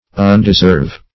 Search Result for " undeserve" : The Collaborative International Dictionary of English v.0.48: Undeserve \Un`de*serve"\, v. t. [1st pref. un- + deserve.]